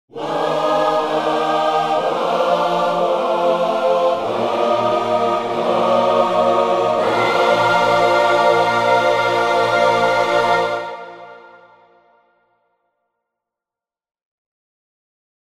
Gospel Whoas demo =1-A02.mp3